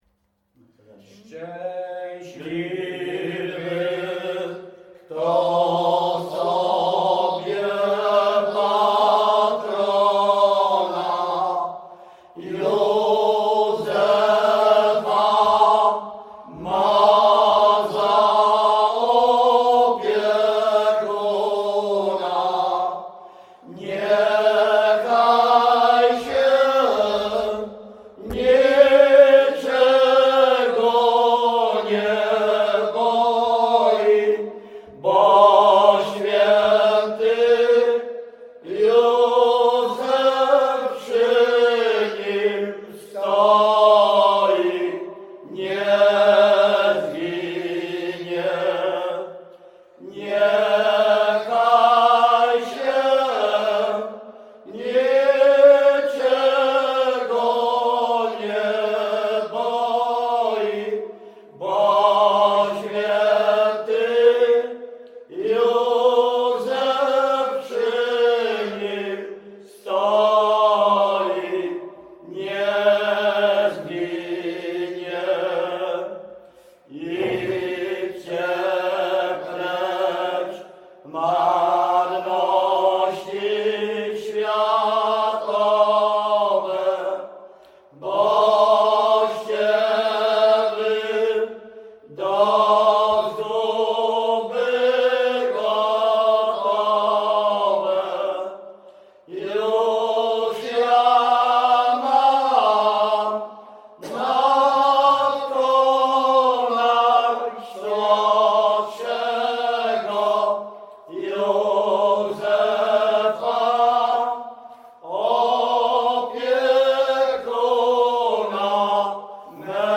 Śpiewacy z Ruszkowa Pierwszego
Wielkopolska, powiat kolski, gmina Kościelec, wieś Ruszków Pierwszy
Pogrzebowa
pogrzebowe nabożne katolickie do grobu o świętych